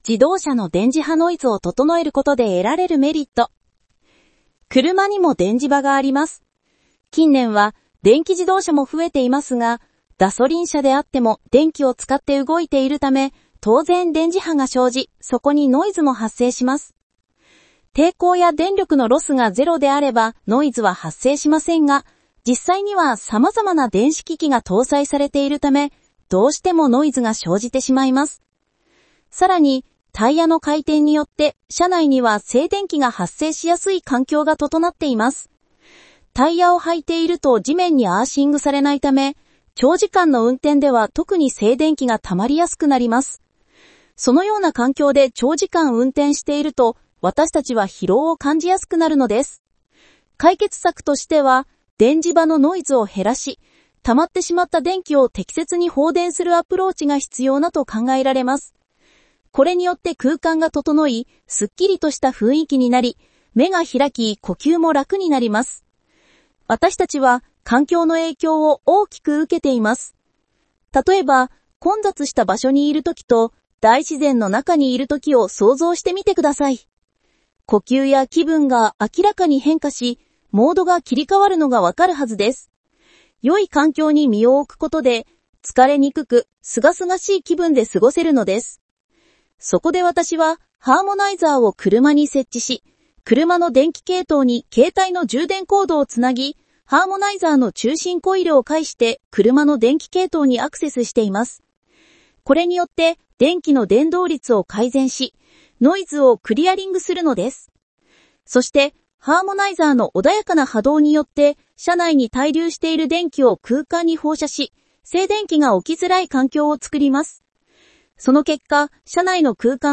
音声ガイダンス